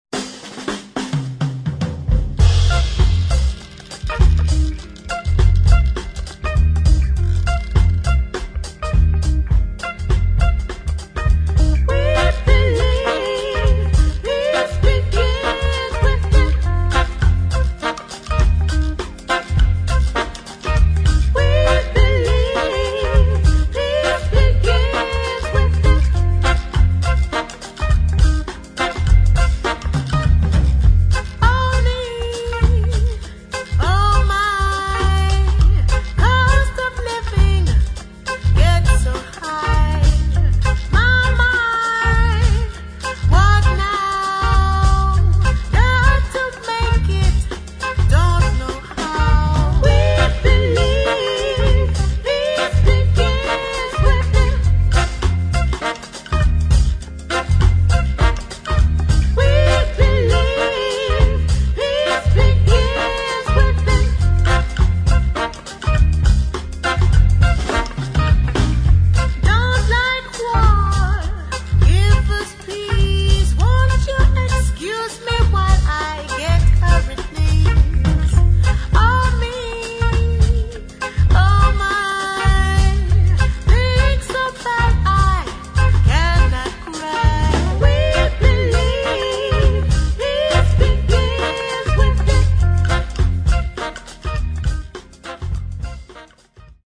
[ JAZZ / REGGAE ]